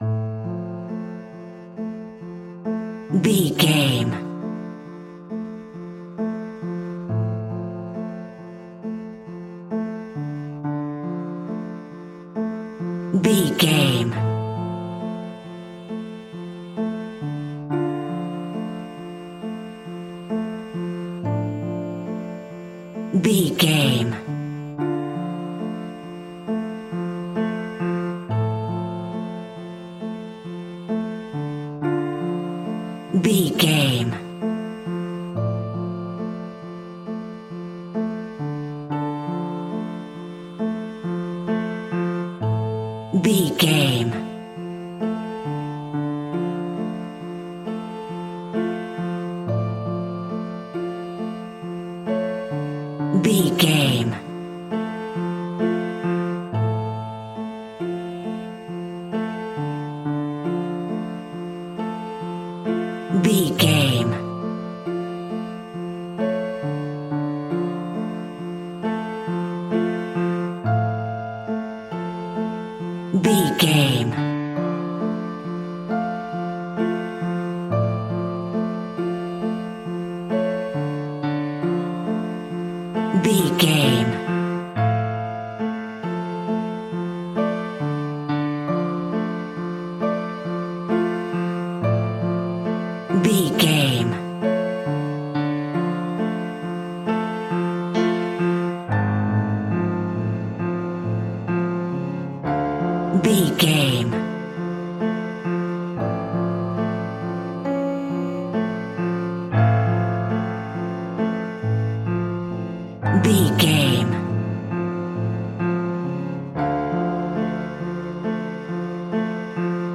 Aeolian/Minor
D
tension
ominous
dark
haunting
eerie
ethereal
horror
Acoustic Piano